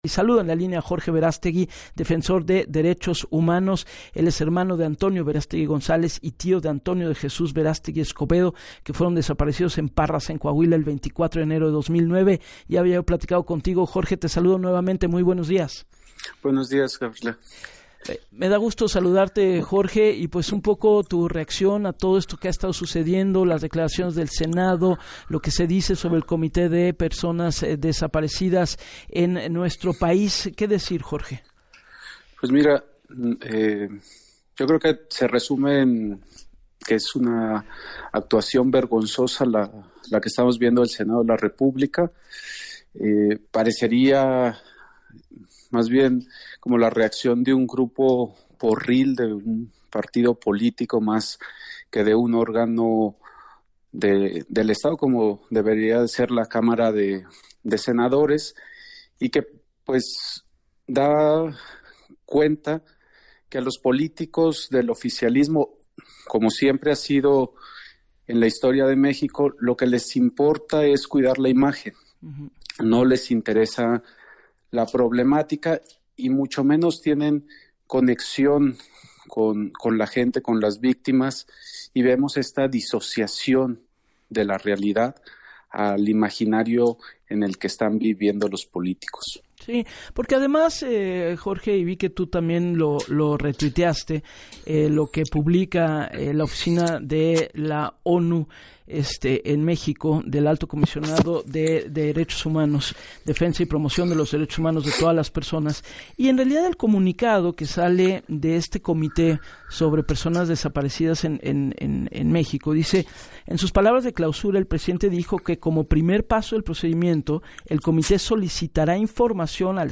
En entrevista con Gabriela Warkentin